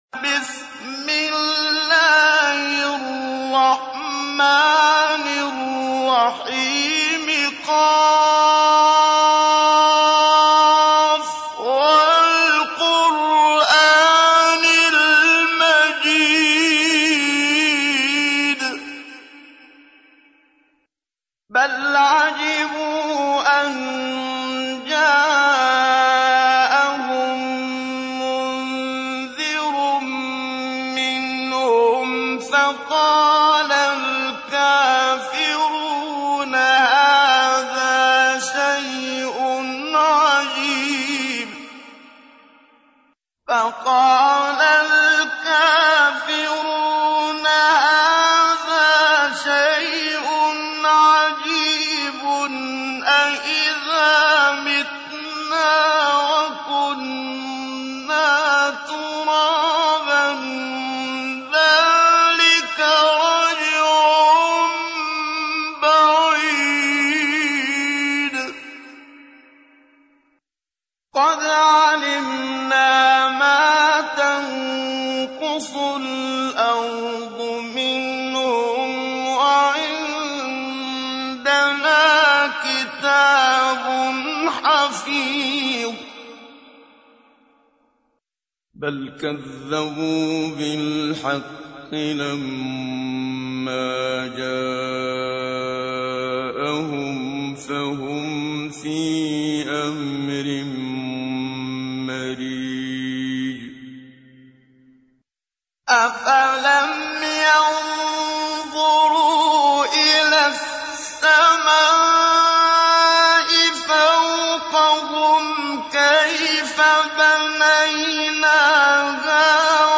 تحميل : 50. سورة ق / القارئ محمد صديق المنشاوي / القرآن الكريم / موقع يا حسين